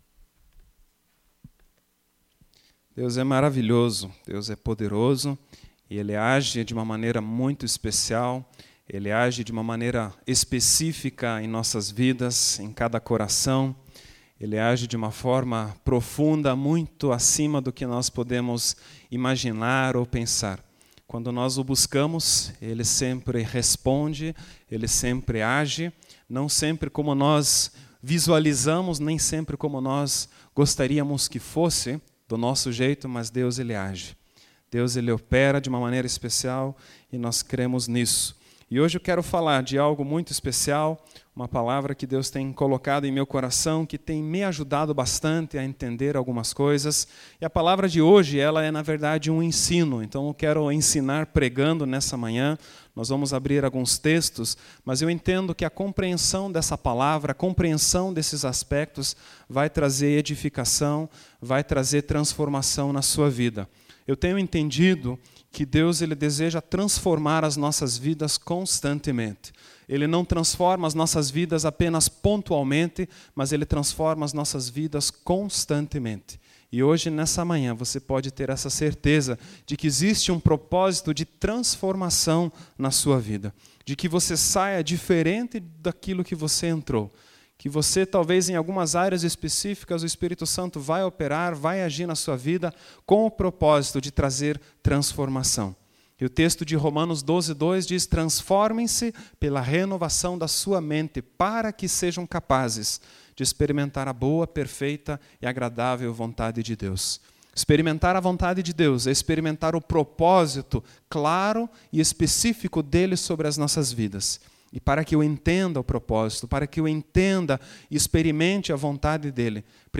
Culto 19/12/2010